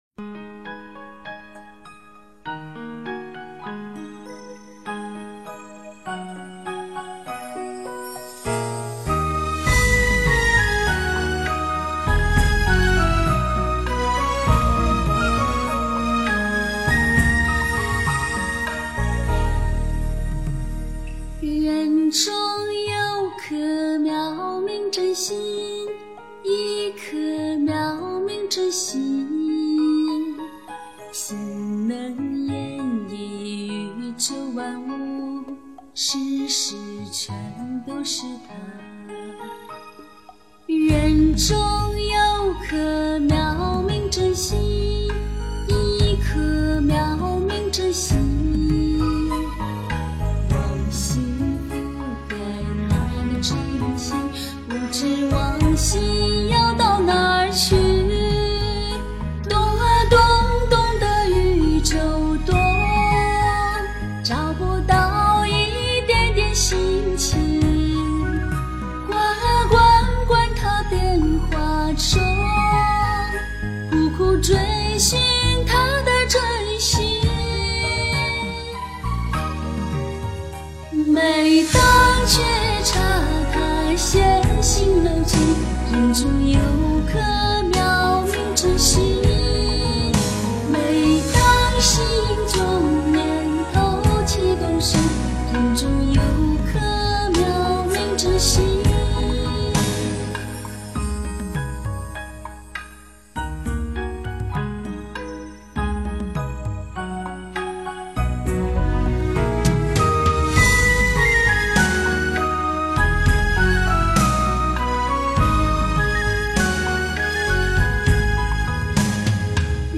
人中有颗妙明真心 诵经 人中有颗妙明真心--佛教音乐 点我： 标签: 佛音 诵经 佛教音乐 返回列表 上一篇： 轮回 下一篇： 生死不离 相关文章 盂兰盆经1--梦参法师 盂兰盆经1--梦参法师...